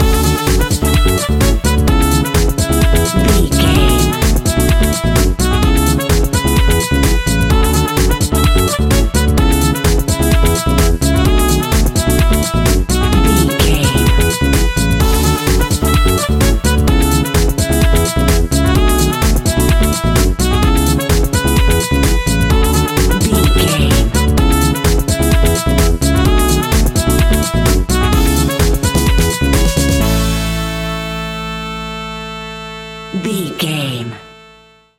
Ionian/Major
groovy
uplifting
bouncy
electric guitar
horns
bass guitar
drums
disco
upbeat
clavinet
fender rhodes
synth bass